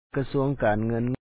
kasúaŋ kaan-ŋǝ́n Ministry of Finance